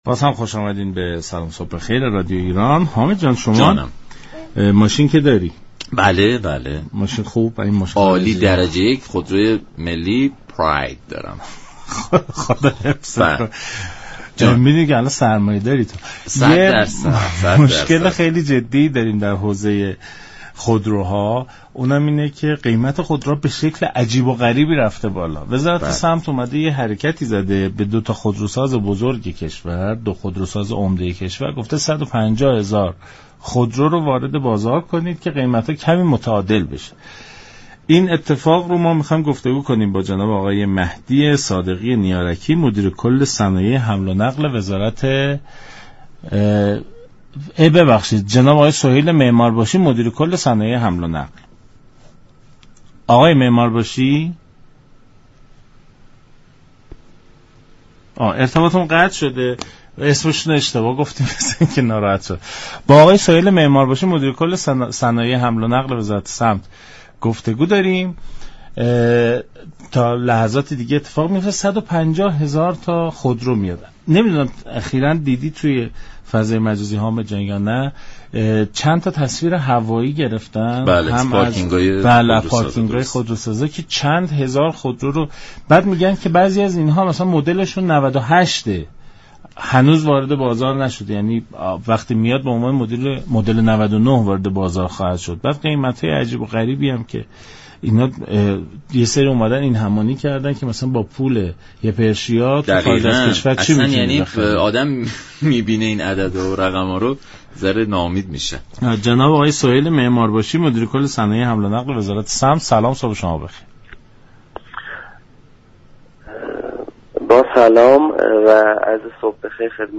به گزارش شبكه رادیویی ایران، «سهیل معمار باشی» مدیر كل صنایع حمل و نقل وزارت صمت در برنامه «سلام صبح بخیر» از برنامه فروش فوق‌العاده 150 هزار دستگاه خودرو با مدت زمان تحویل یك تا سه ماهه تا پایان سال جاری خبر داد و گفت: به منظور كاهش التهابات بازار خودرو، از مهرماه تا پایان سال جاری، 152 هزار دستگاه خودرو به صورت فروش فوق‌العاده در اختیار مردم قرار خواهد گرفت.